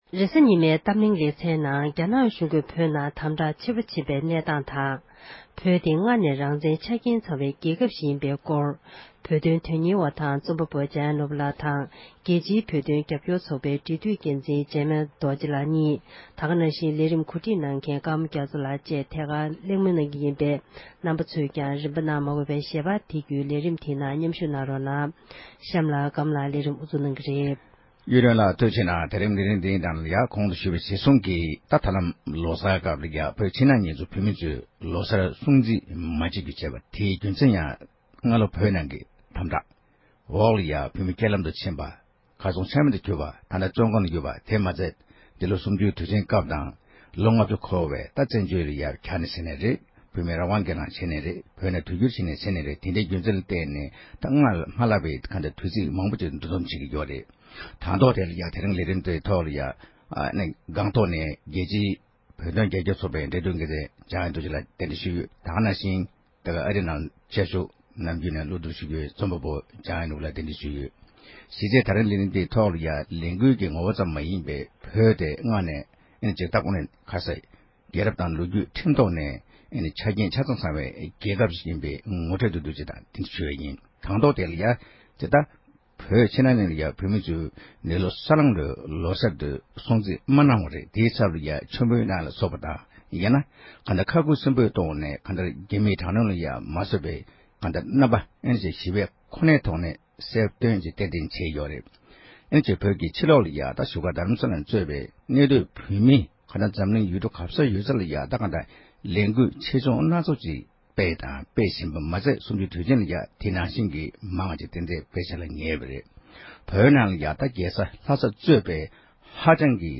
གཏམ་གླེང